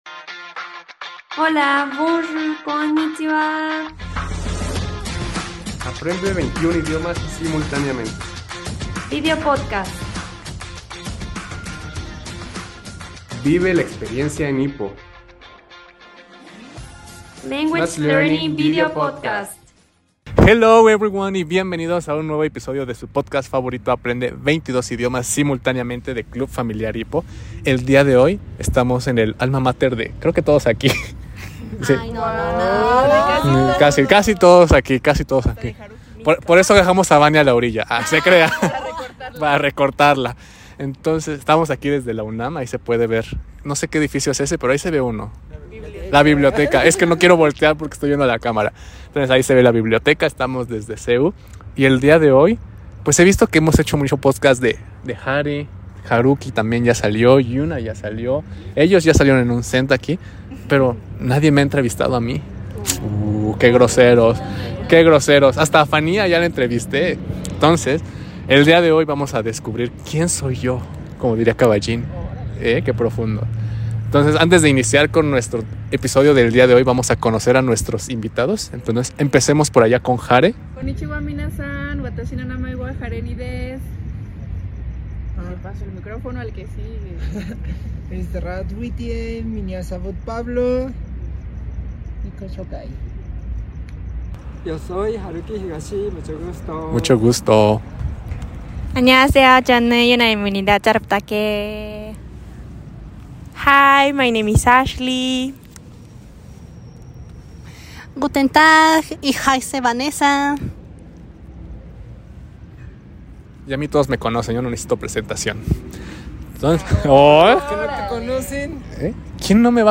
¿Qué sucede cuando los interns entrevistan al entrevistador?